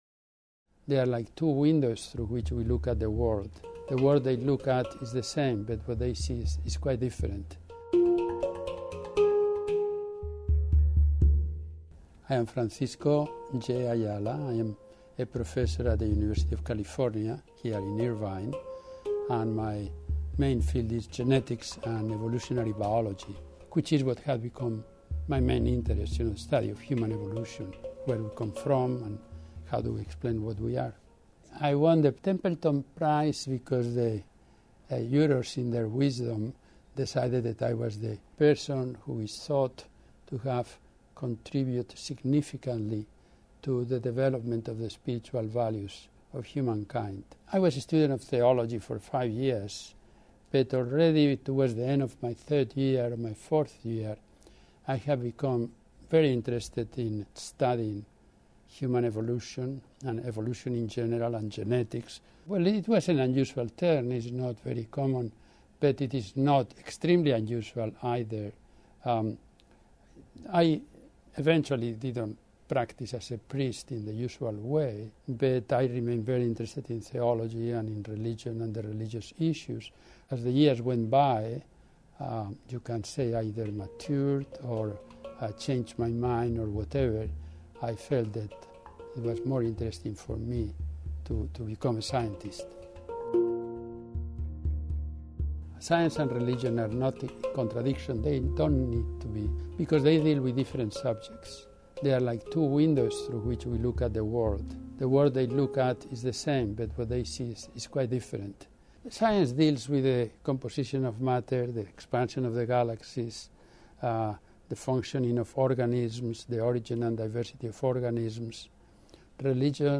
From Priest to Scientist: An Interview with Dr. Francisco J. Ayala